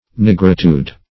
Search Result for " nigritude" : The Collaborative International Dictionary of English v.0.48: Nigritude \Nig"ri*tude\, n. [L. nigritudo, fr. niger black.] Blackness; the state of being black.